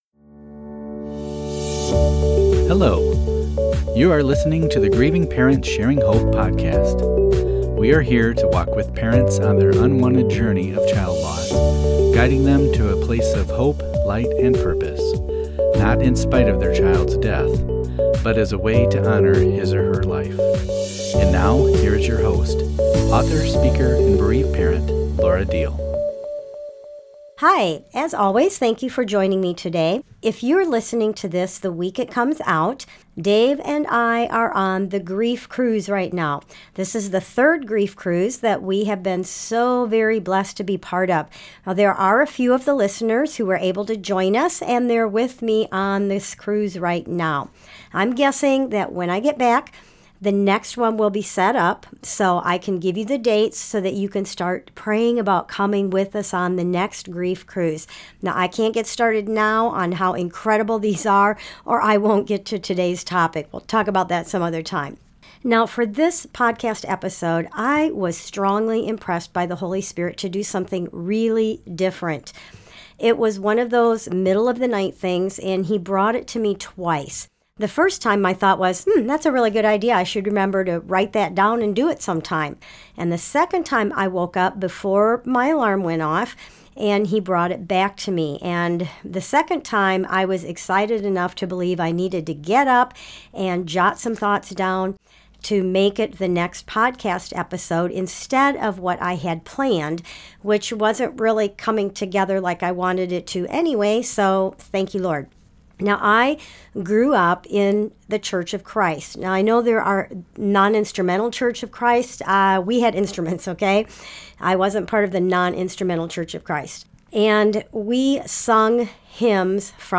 You may even hear her break out in song a couple of times.